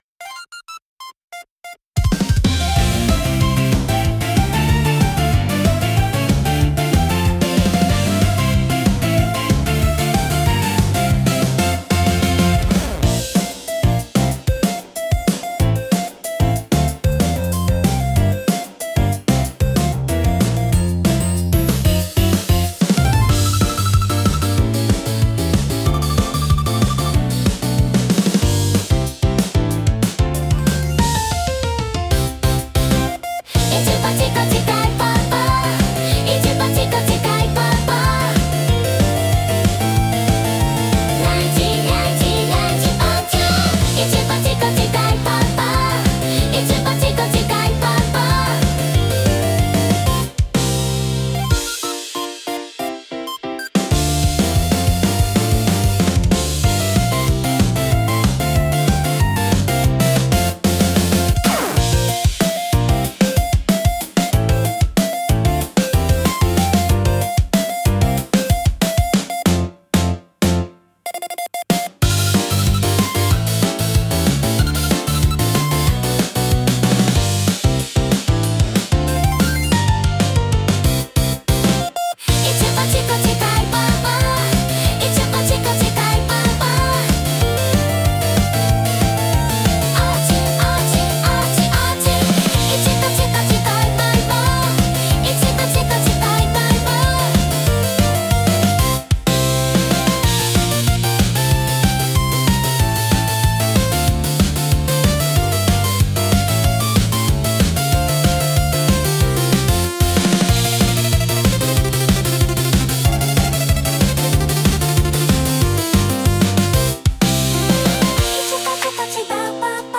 親しみやすく軽快な空気感を演出したい場面で活躍します。